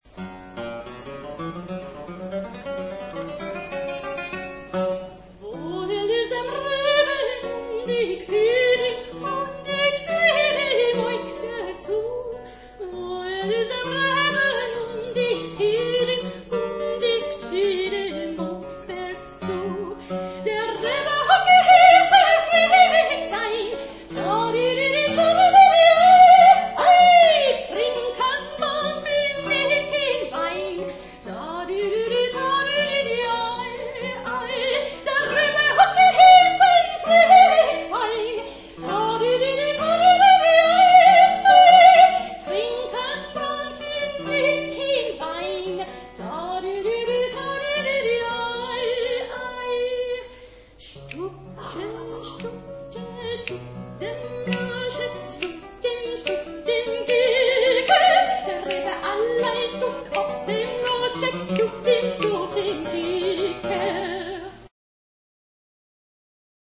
They are mostly live extracts from public performances.
(live extract)
for voice and guitar